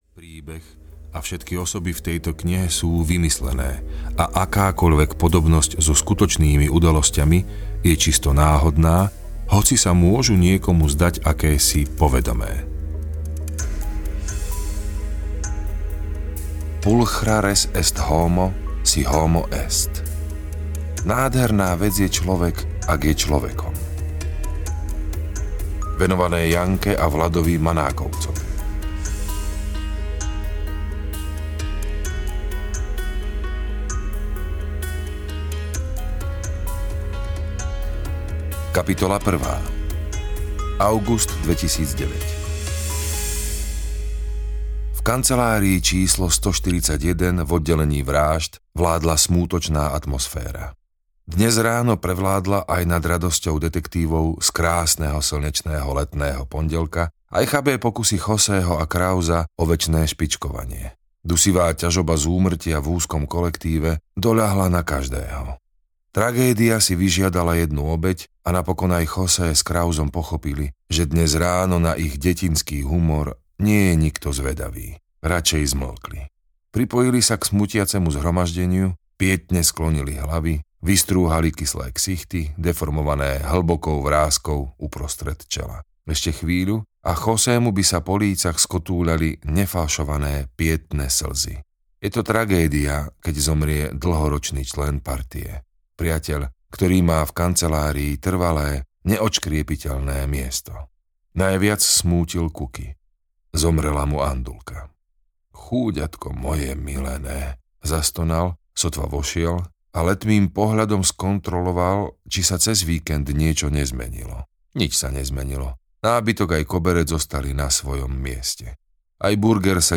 Venuša zo zátoky audiokniha
Ukázka z knihy